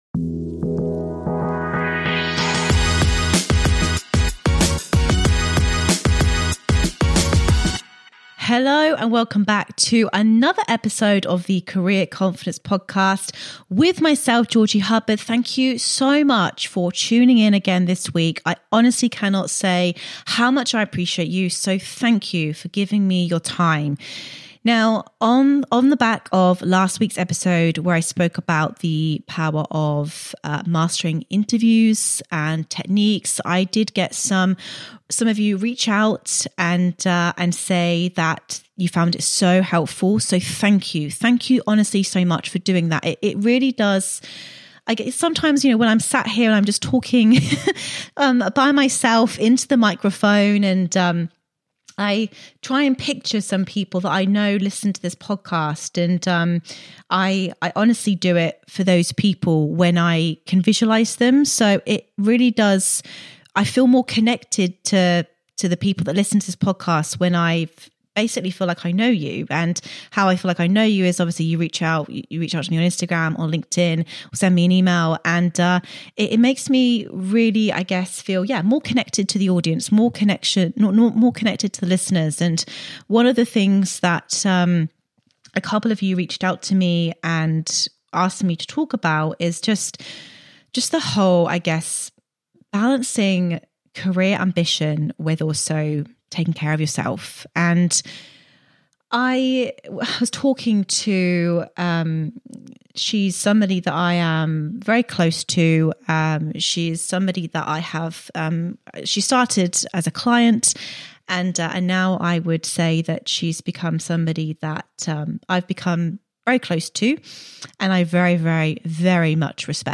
solo episode